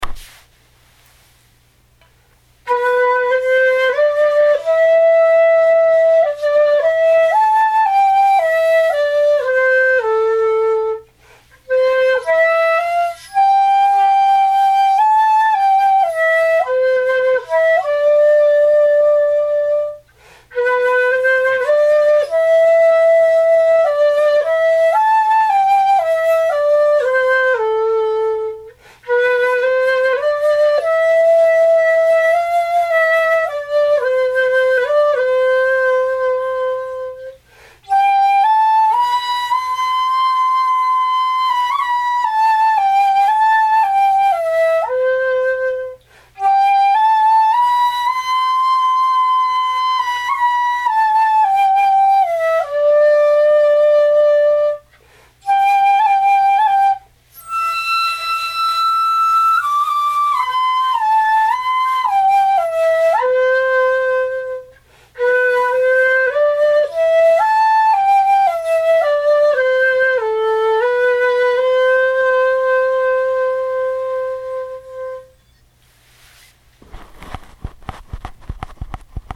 揺り（ビブラート）
[曲danny boy 6寸管]。
まず、横揺りだけを、、、